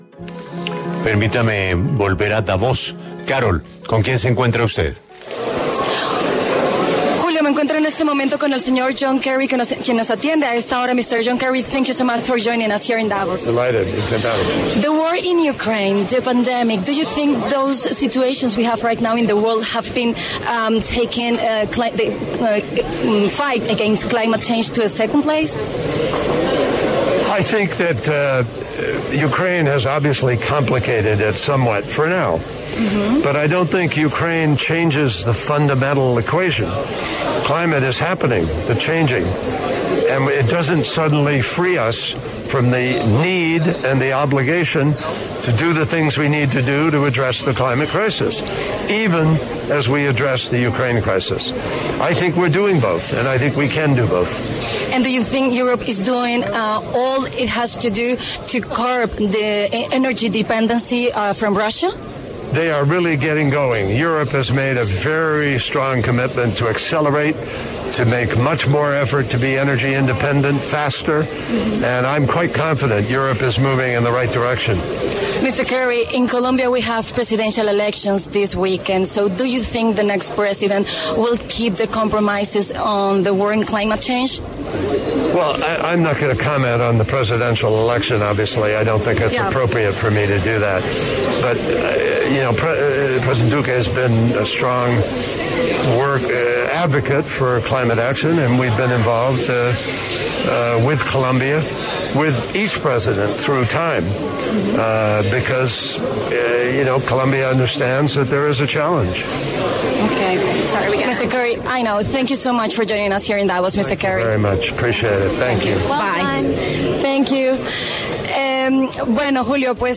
En el encabezado escuche la entrevista completa con John Kerry, enviado presidencial especial de Estados Unidos para el Clima.